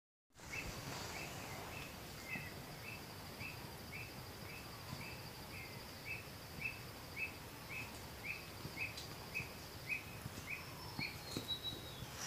Tingazú (Piaya cayana)
Nombre en inglés: Common Squirrel Cuckoo
País: Argentina
Localidad o área protegida: Rincon Del Socorro
Condición: Silvestre
Certeza: Vocalización Grabada